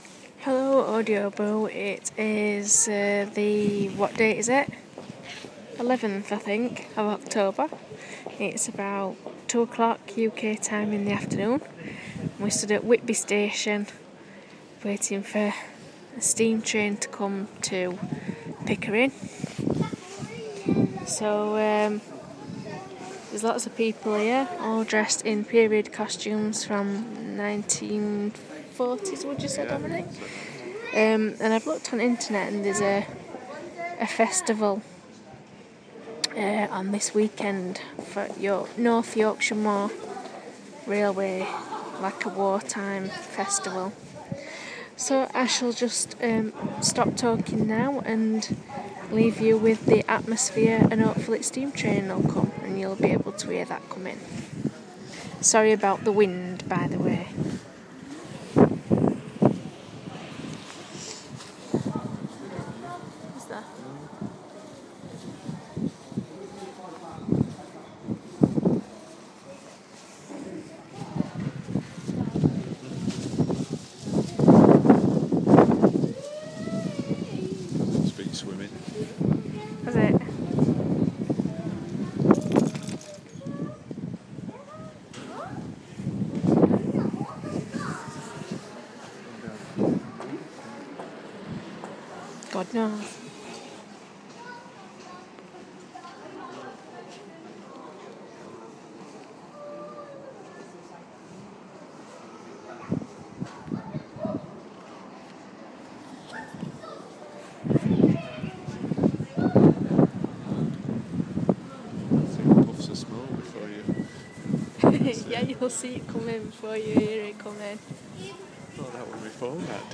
Steam train